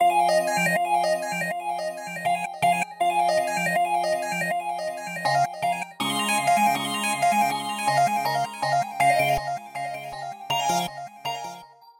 描述：调：Fmin 速度：80bpm 前面的一个劈叉。
Tag: 80 bpm Chill Out Loops Synth Loops 2.02 MB wav Key : F